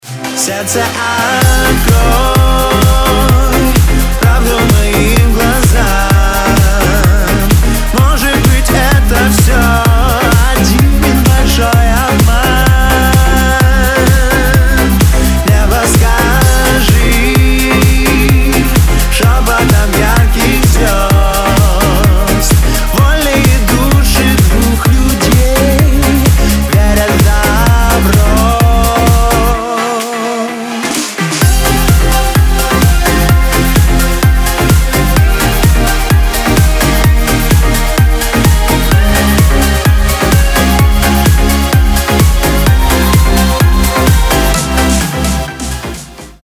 • Качество: 320, Stereo
саундтрек